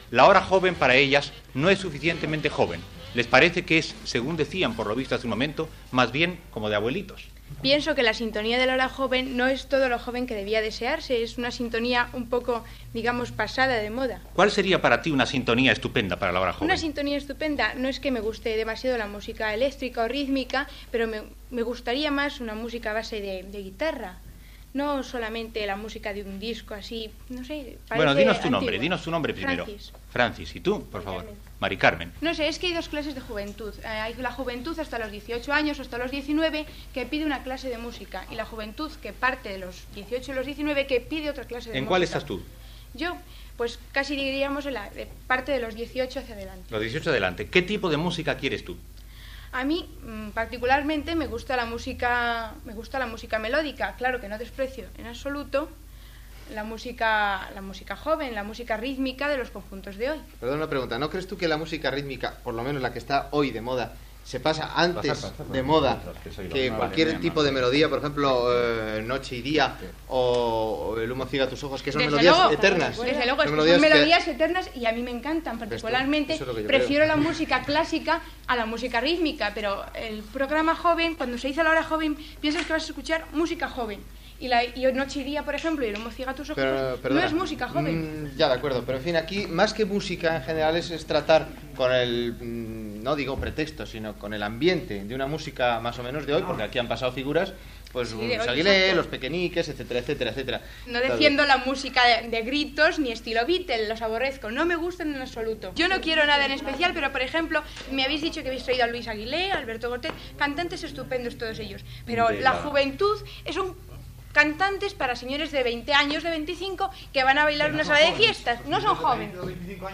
Comentari sobre la sintonia del programa i alguns artistes que han anat al programa, fet per un parell de noies joves